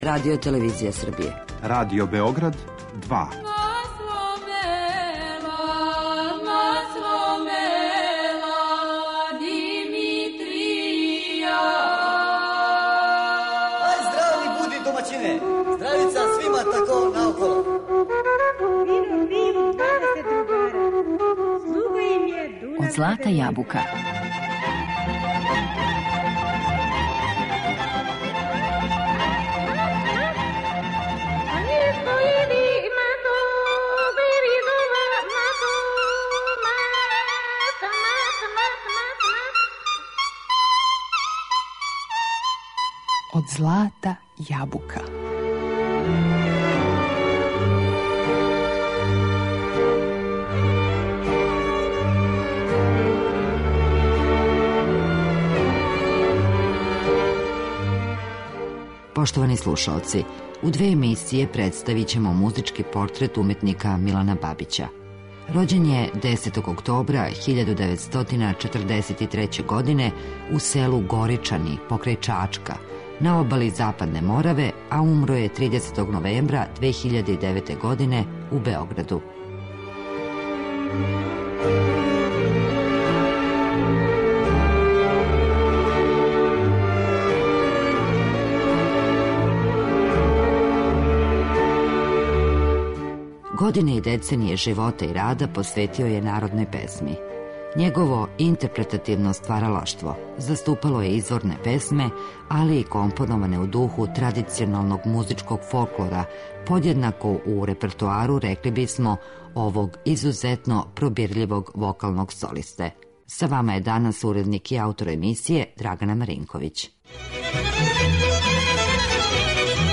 музички портрет
Музичка публика ће га памтити као врсног интерпретатора изворних песама и компонованих у духу народне традиције, али и као великог борца за очување изворне народне музике.